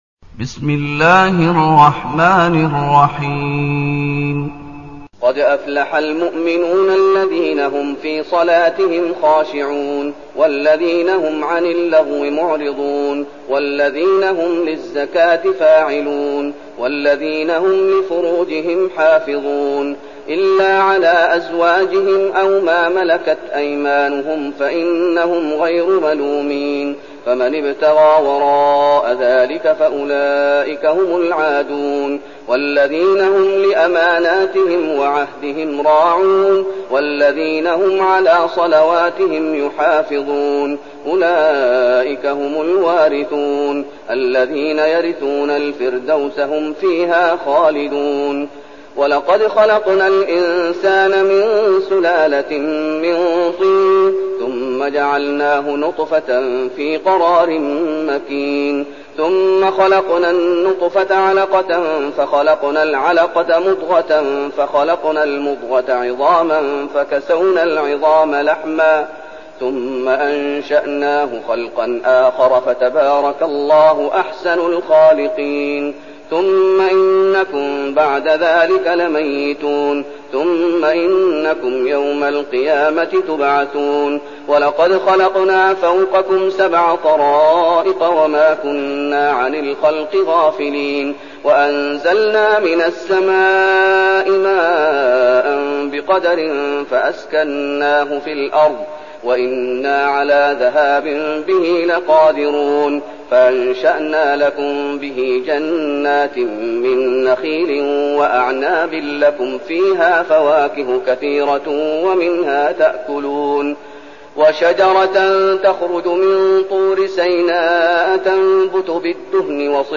المكان: المسجد النبوي الشيخ: فضيلة الشيخ محمد أيوب فضيلة الشيخ محمد أيوب المؤمنون